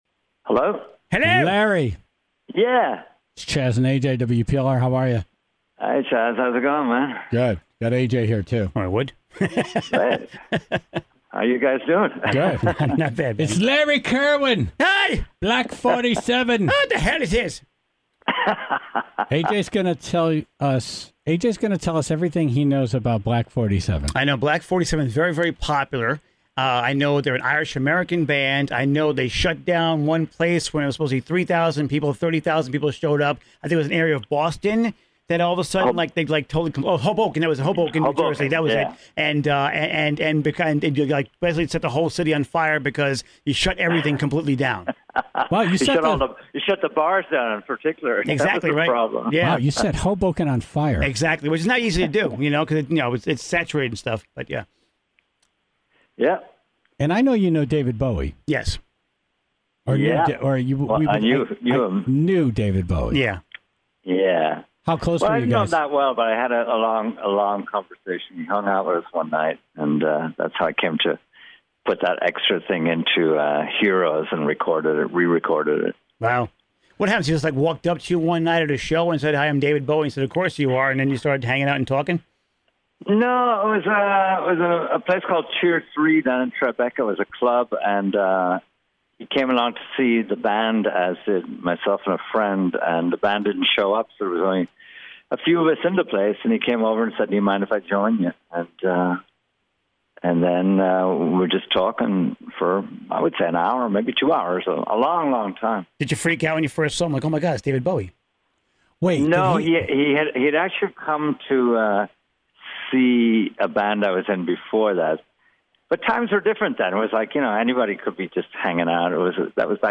Interview with Black 47's Larry Kirwin